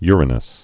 (yrə-nəs) also u·ri·nose (-nōs)